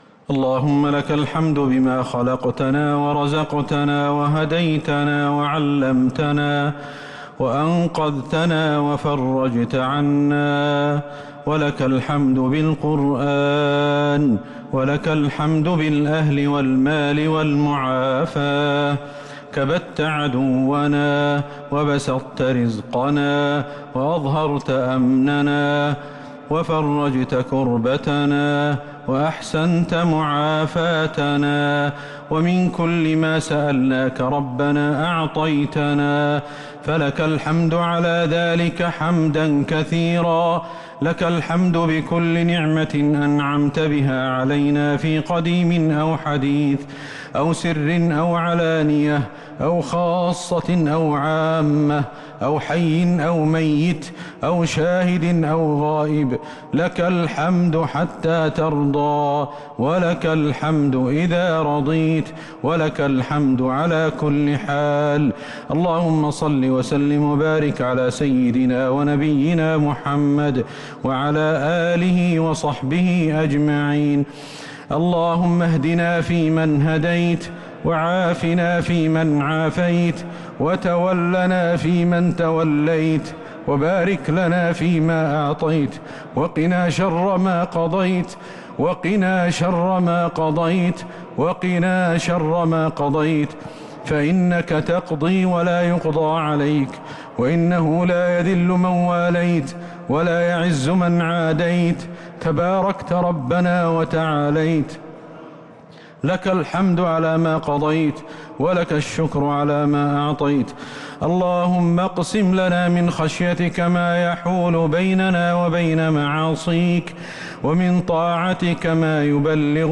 دعاء القنوت ليلة 18 رمضان 1443هـ | Dua for the night of 18 Ramadan 1443H > تراويح الحرم النبوي عام 1443 🕌 > التراويح - تلاوات الحرمين